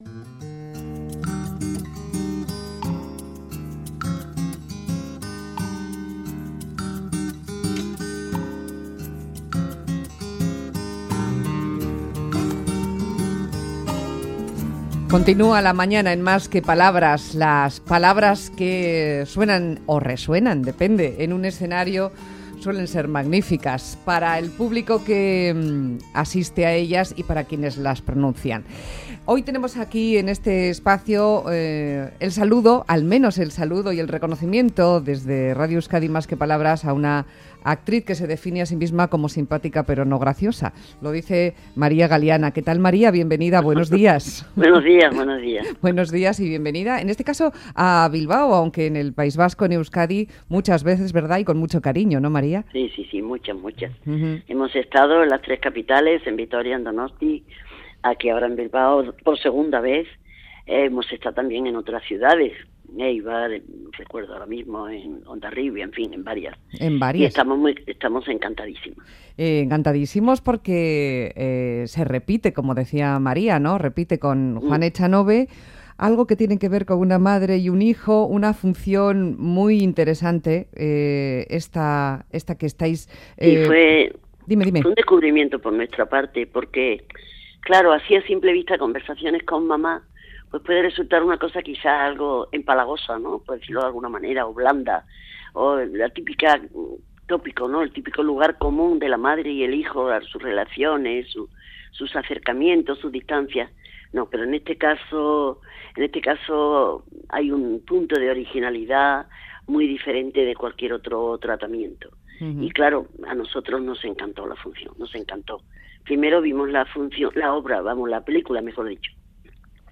María galiana conversaciones con mamá Juan echanove Bilbao entrevista
Conversamos con la actriz María Galiana, que llega al Campos Elíseos de Bilbao con la obra "Conversaciones con mamá". Está agotada de funciones | Radio Euskadi